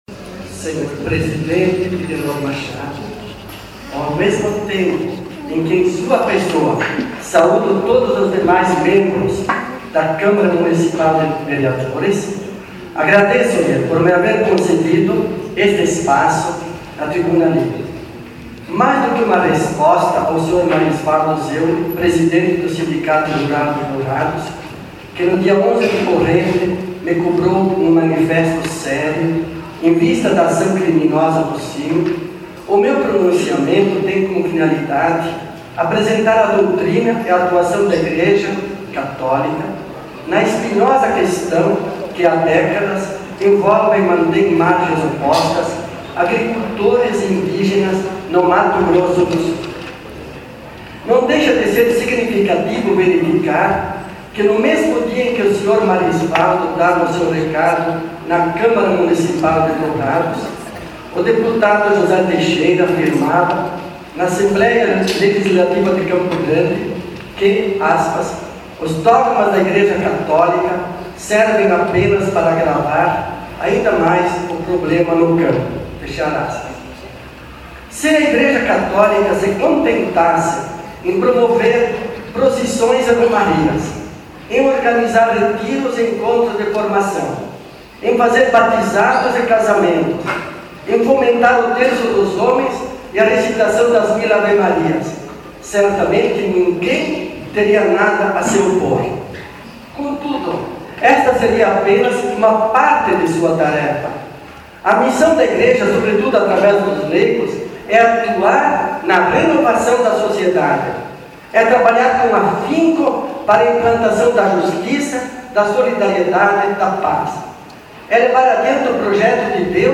No final do discurso, dom Redovino foi muito aplaudido.